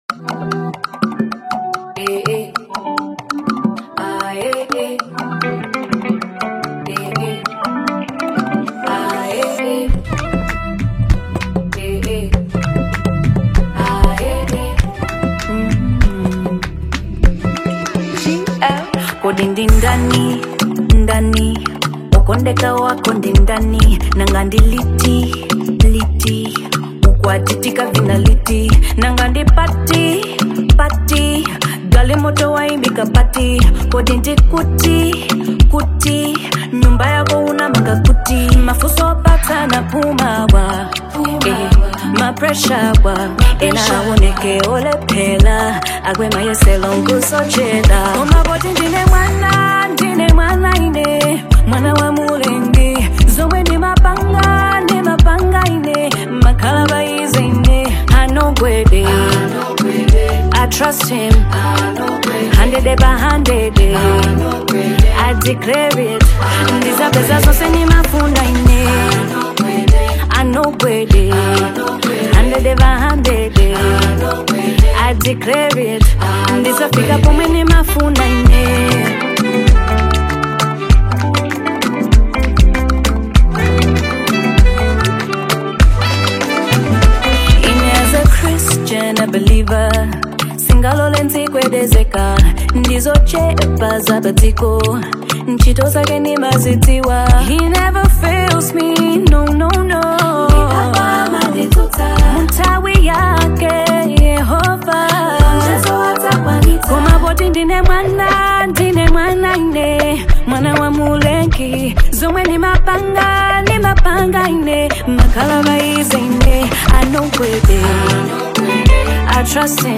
soothing yet passionate vocals carry a sense of reassurance